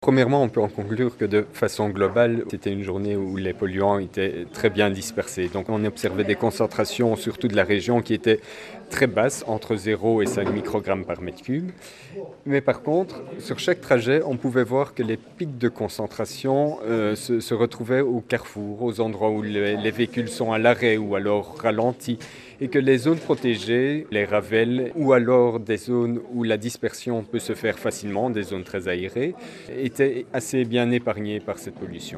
Reportages produits par la RTBF-Vivacité :
reportage 3